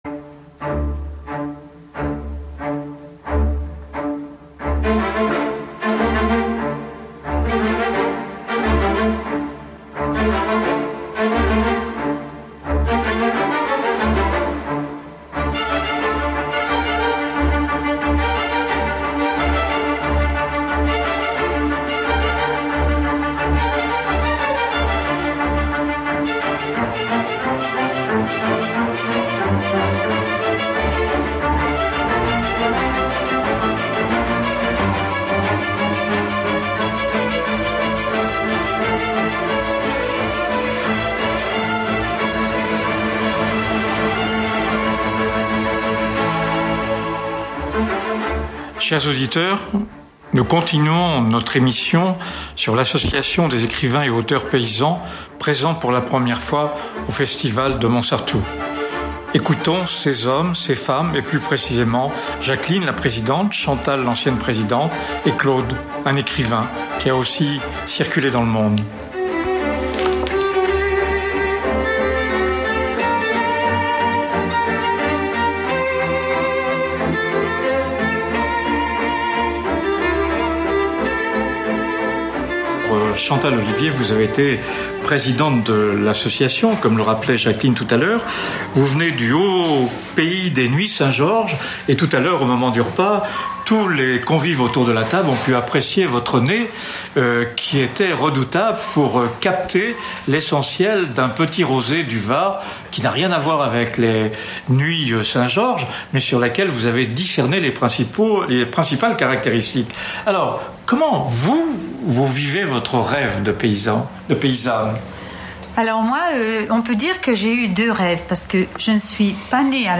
Enregistré au Festival du Livre de Mouans-Sartoux